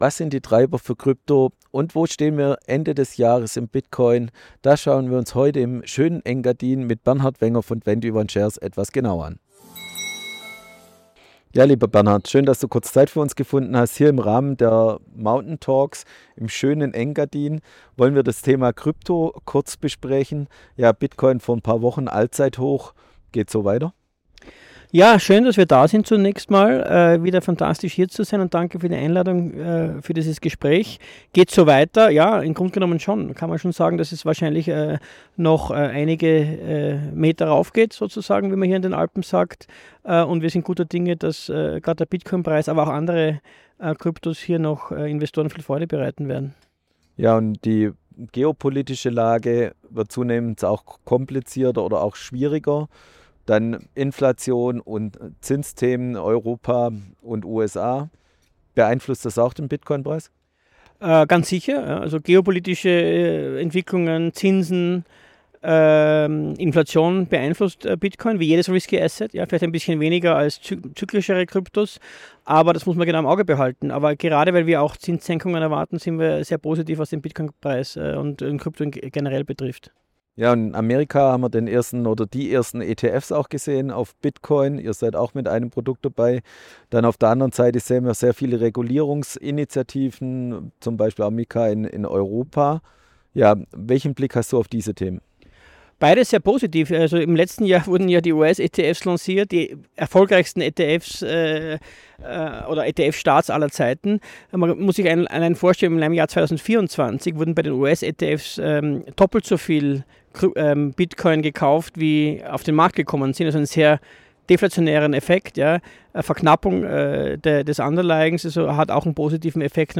Im Gespräch geht es um Kursprognosen, die wichtigsten